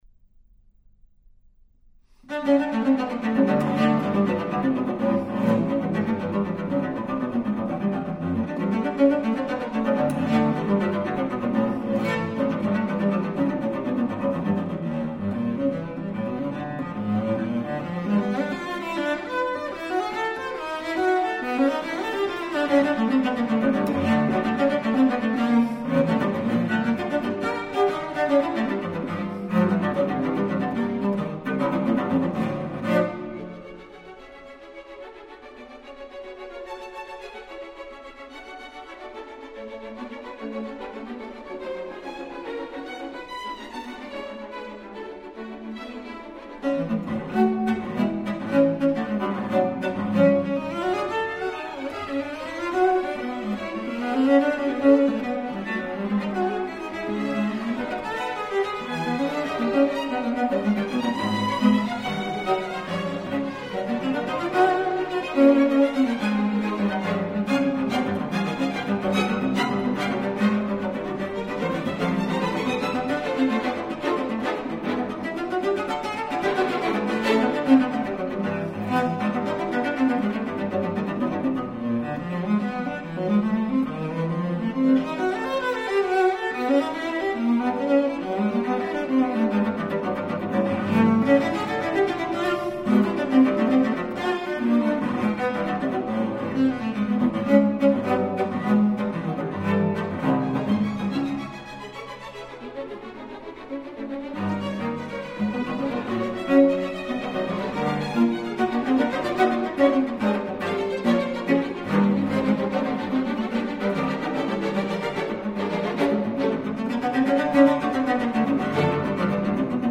each featuring an unsung cello concerto
combined with works for cello and piano.